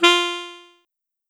82828 Sax